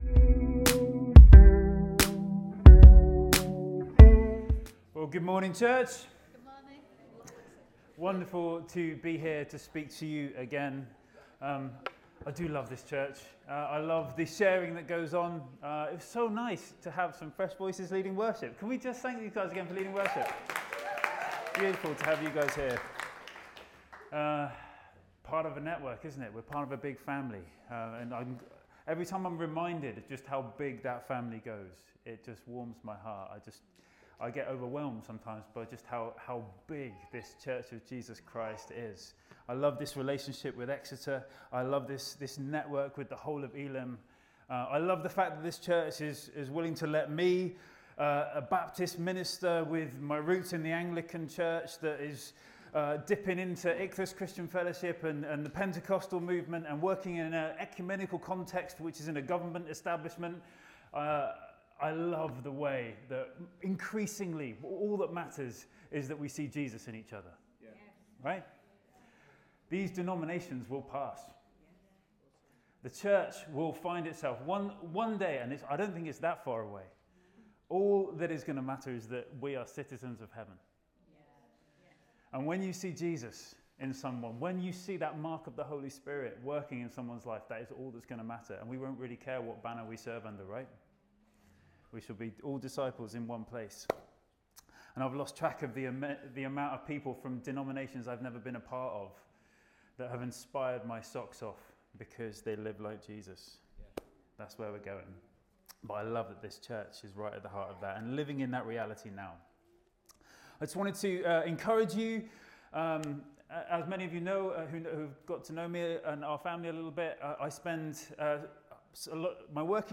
Sunday Messages